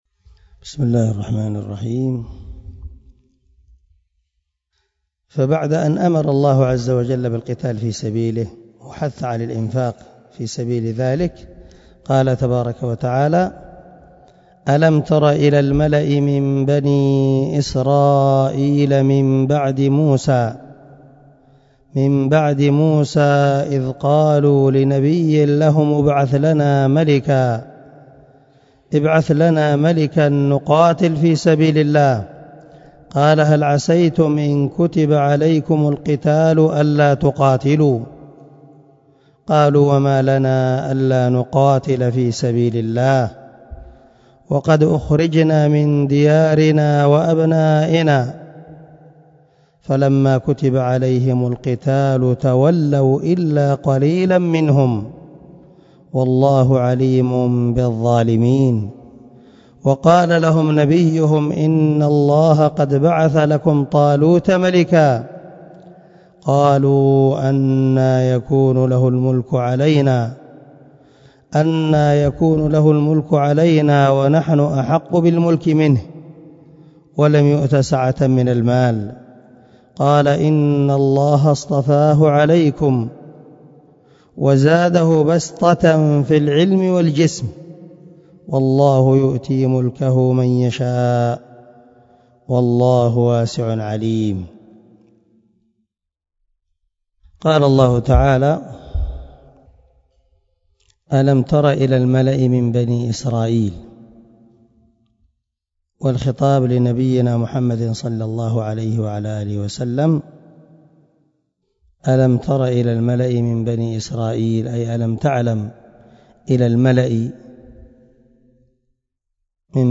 128الدرس 118 تفسير آية ( 246 – 247 ) من سورة البقرة من تفسير القران الكريم مع قراءة لتفسير السعدي